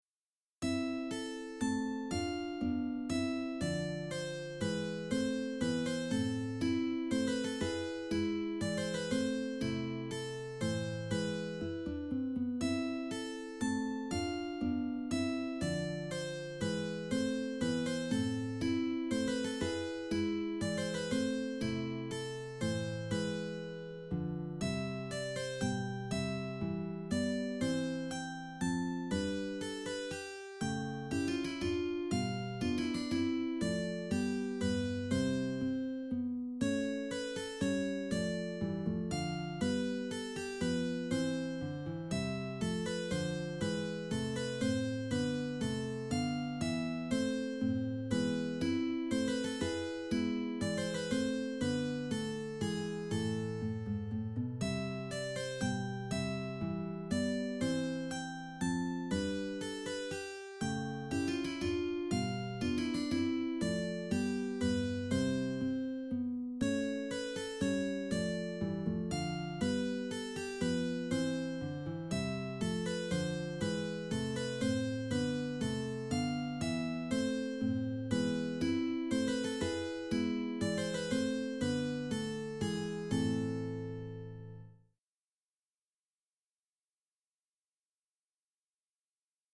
Here are nos. 1,3 & 15 arranged for mandolin and classcial guitar, along with computer-generated "performances" in mp3 format.